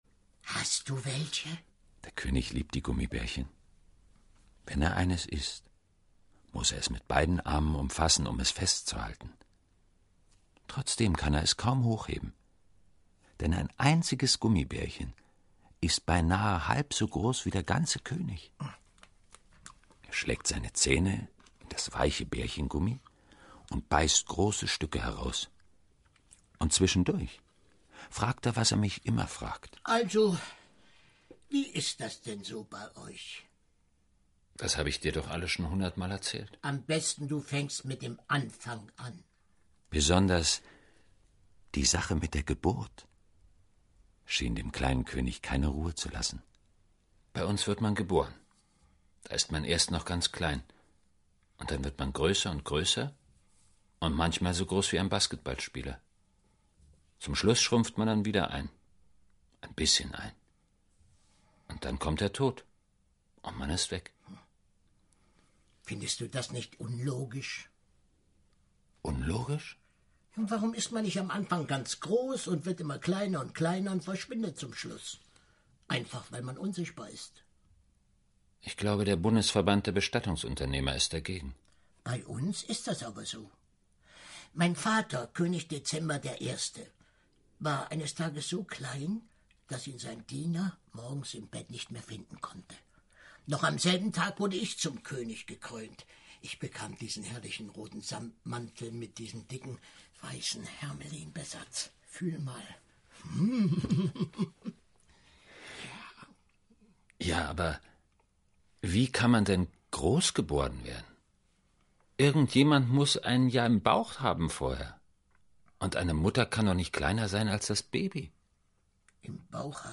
Hörspiel, 1 Audio-CD
"Äußerst glaubwürdig spielt Horst Bollmann den zetrigen und doch liebenswerten Mini-König, dessen Fragen sich nicht nur an den frustrierten Erzähler richten: „Was ist nun die Wahrheit: Bist du so groß, wie du aussiehst, oder so klein, wie du dich fühlst?“" --STERN